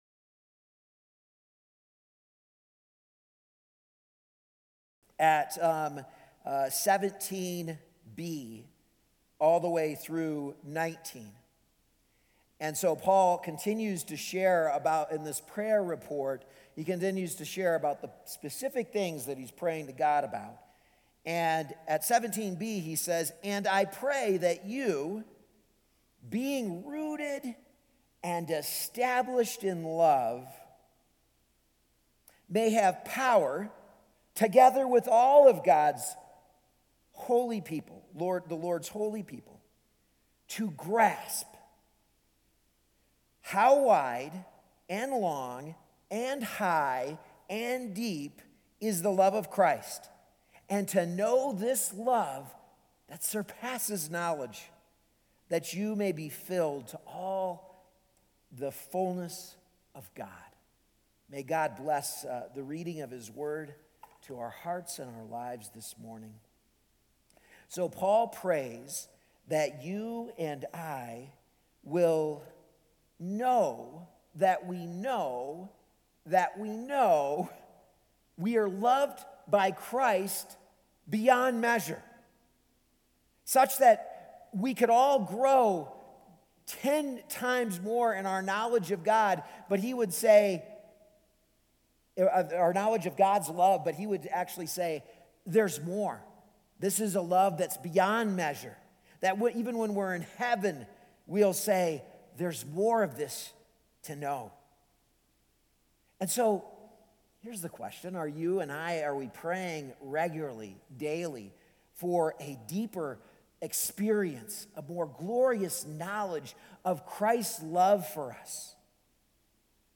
A message from the series "Dearly Loved."